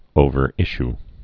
(ōvər-ĭsh)